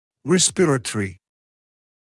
[‘respɪrətrɪ][‘респирэтри]дыхательный, респираторный